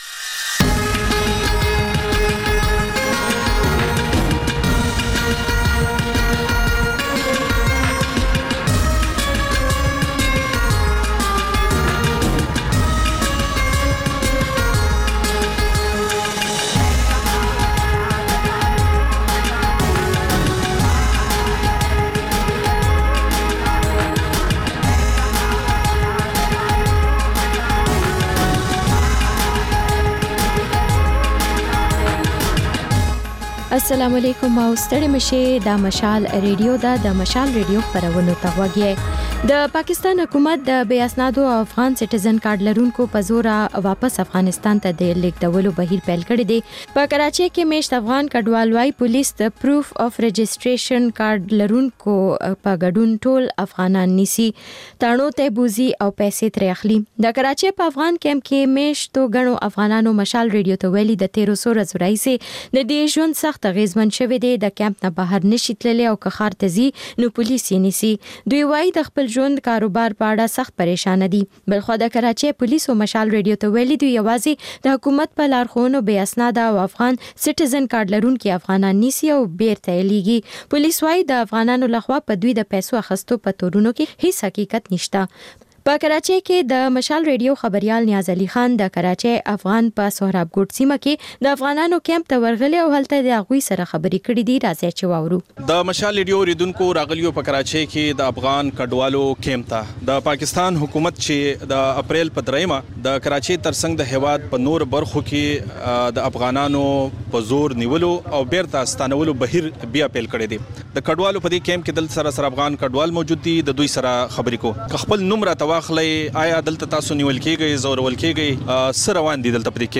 په دې خپرونه کې تر خبرونو وروسته بېلا بېل رپورټونه، شننې او تبصرې اورېدای شئ.